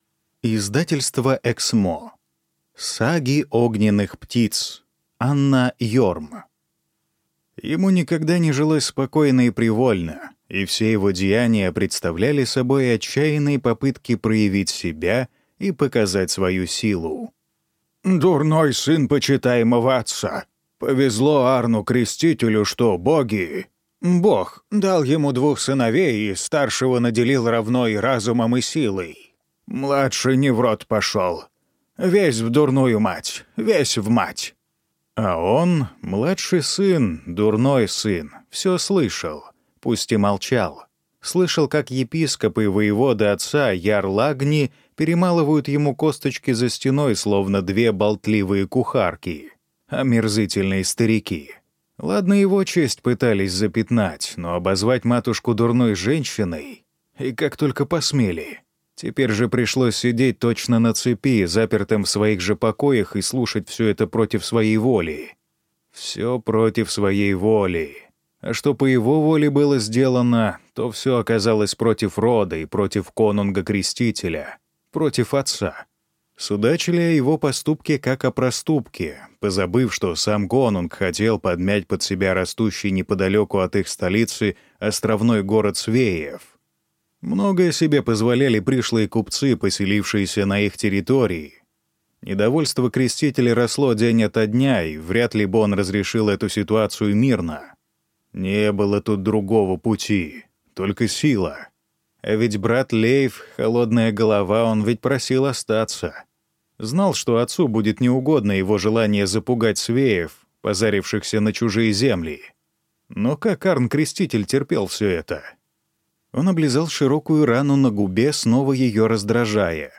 Аудиокнига Саги огненных птиц | Библиотека аудиокниг